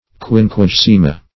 Quinquagesima \Quin`qua*ges"i*ma\, a. [L., fr. quinquagesimus